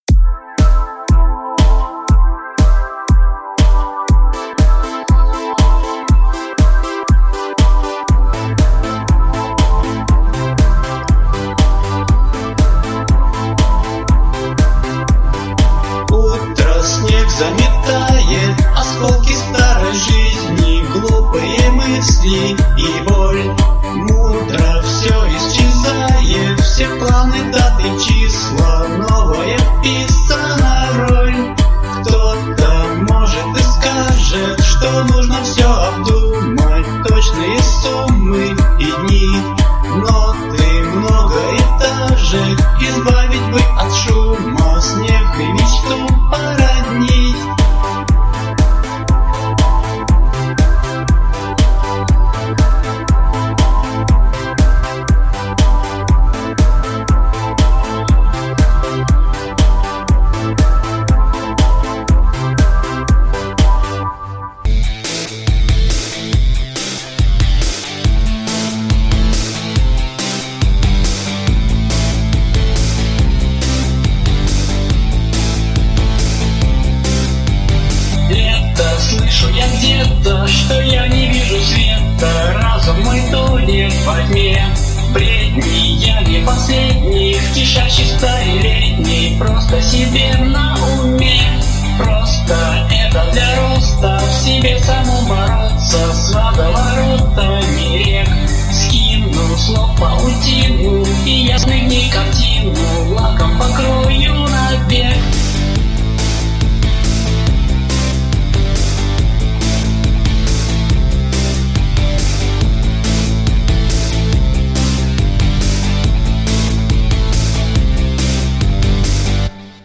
Pop & Rock
Приятный голос (несмотря на плохой микрофон)